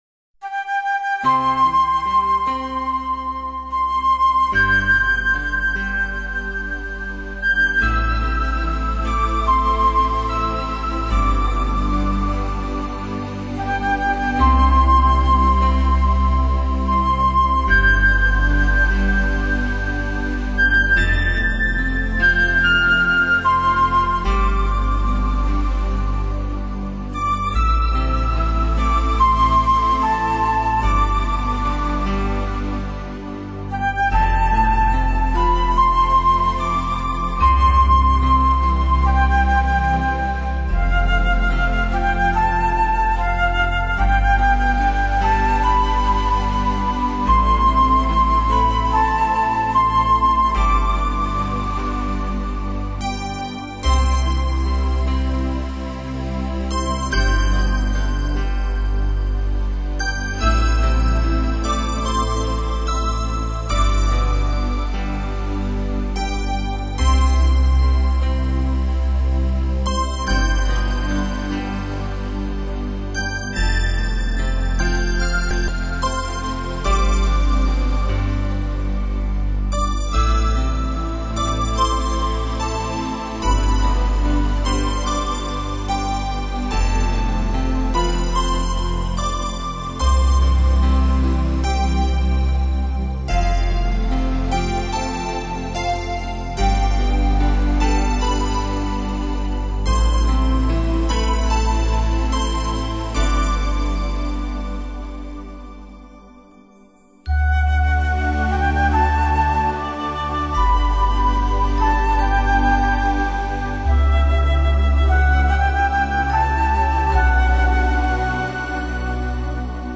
将你带到充满朦胧美感的音乐世界里，在白蔼蔼的雾色中，享受万籁俱寂的宁静时刻。
去好好的欣赏这张大碟，感受宁静的氛围。